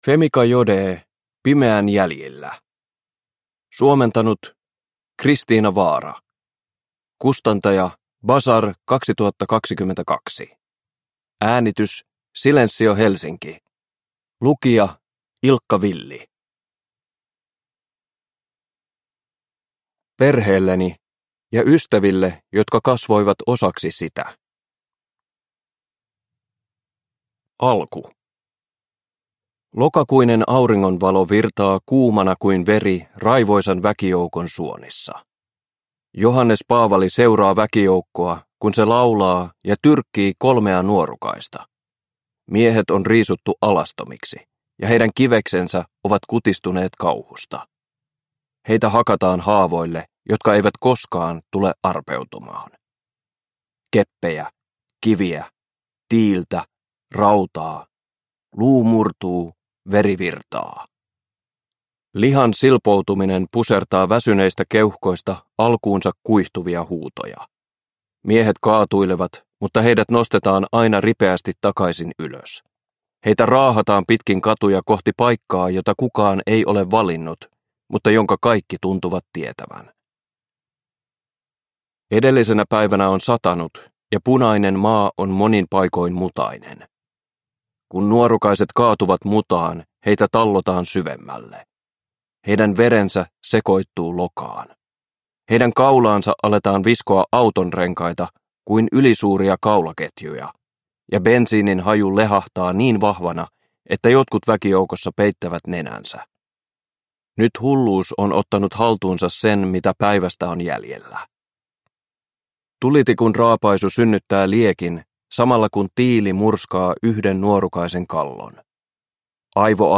Pimeän jäljillä – Ljudbok – Laddas ner